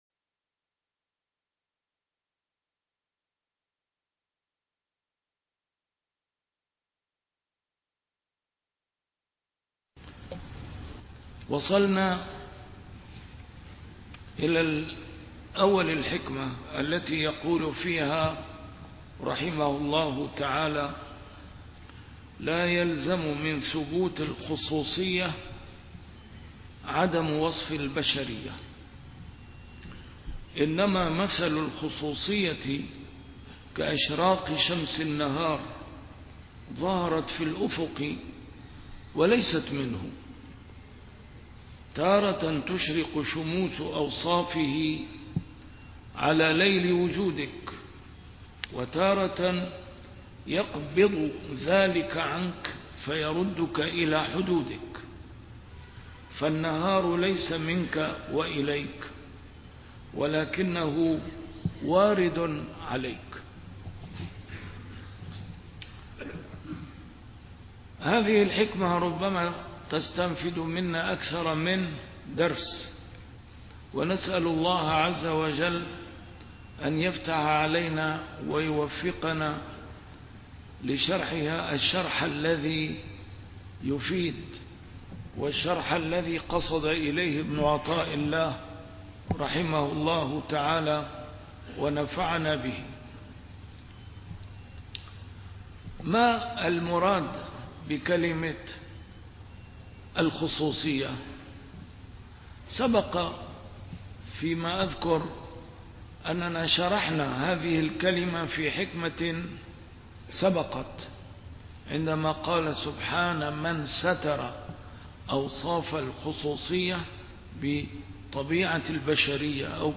A MARTYR SCHOLAR: IMAM MUHAMMAD SAEED RAMADAN AL-BOUTI - الدروس العلمية - شرح الحكم العطائية - الدرس رقم 273 شرح الحكمة رقم 248 (تتمة)